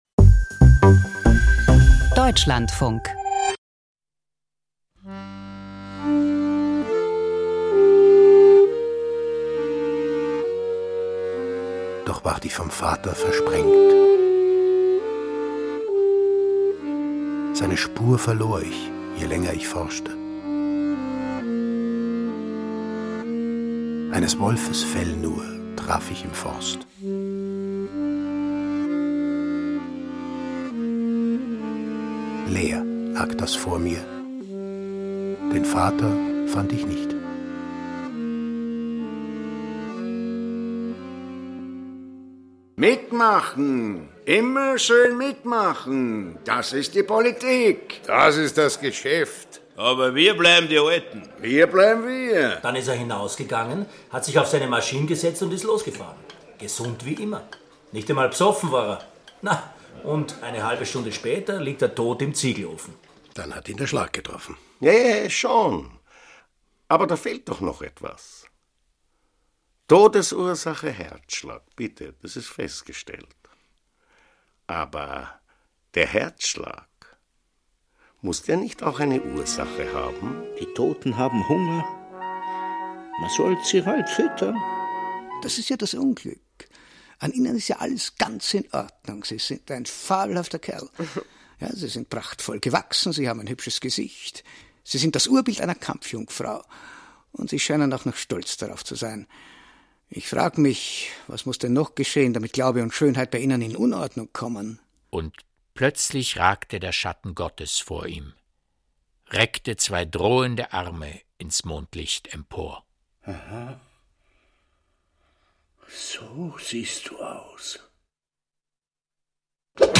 Email Audio herunterladen Verdrängte Kriegsverbrechen, eine eisern schweigende Dorfgemeinschaft und ein Mann, der mit allen Mitteln nach Antworten sucht. Preisgekröntes Hörspiel nach dem Roman von Hans Lebert.